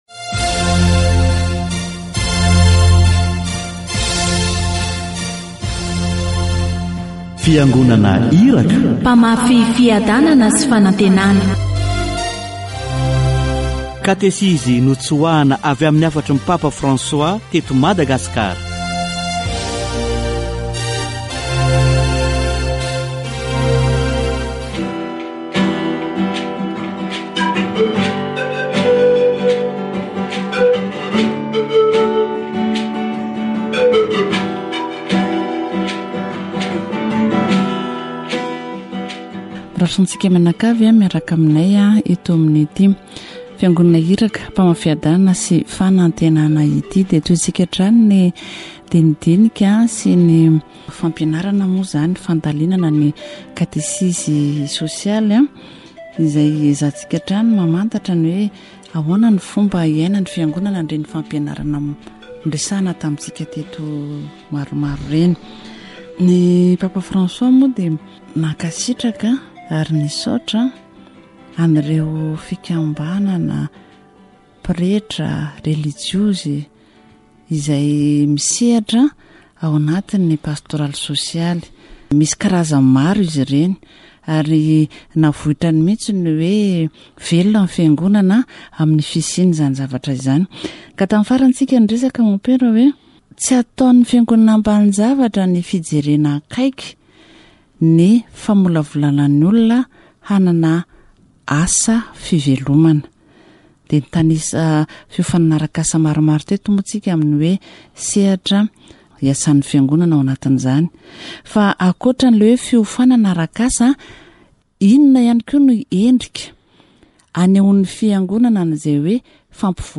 The church educates people to consider the environment by encouraging them to plant trees and reduce air pollution emissions. The church takes care of the people entrusted to it, through services such as medical care, the care of orphans and the handicapped. Catechesis on social ministry